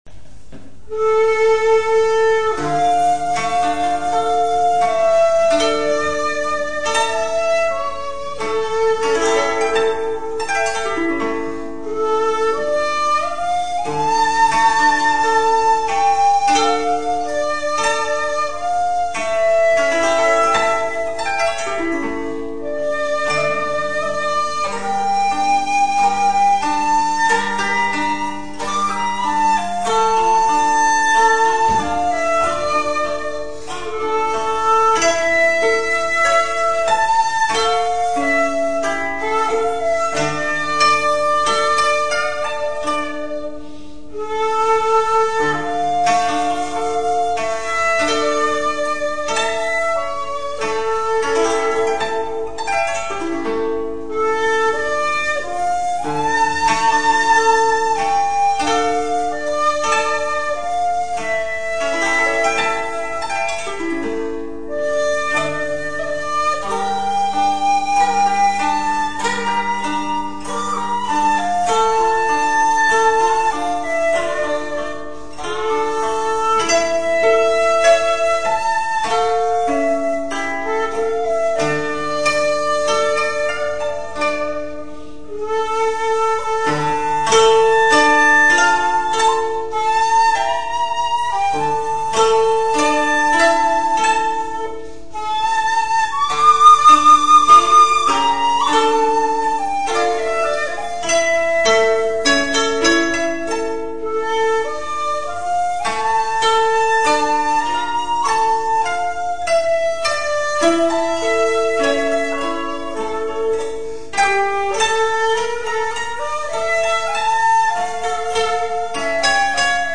月に泣く虫と共に 箏